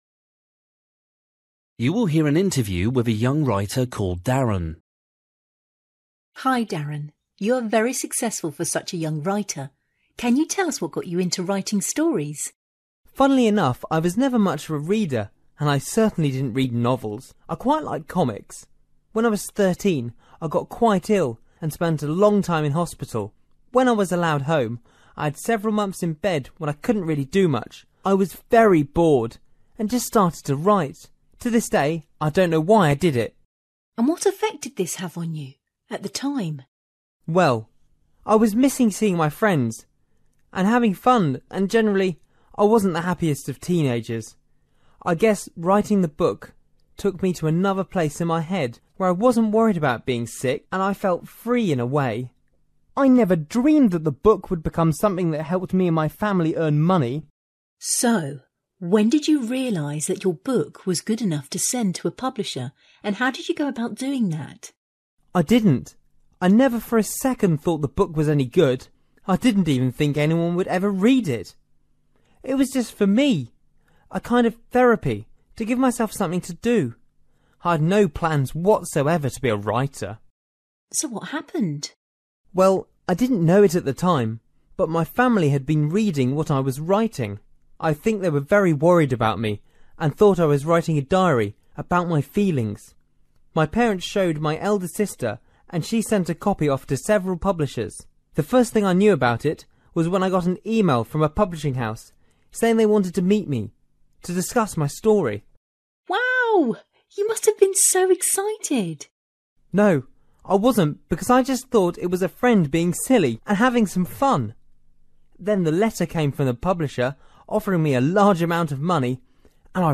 Bài tập trắc nghiệm luyện nghe tiếng Anh trình độ trung cấp – Nghe một cuộc trò chuyện dài phần 21